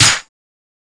• '00s Long Room Reverb Snare Sample C# Key 05.wav
Royality free steel snare drum sample tuned to the C# note. Loudest frequency: 5455Hz
00s-long-room-reverb-snare-sample-c-sharp-key-05-P5i.wav